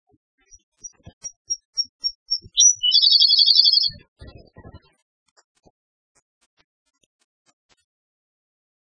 〔コルリ〕チッチッ／チッチッ････チッカララ（さえずり）／山地の下生えの多い落葉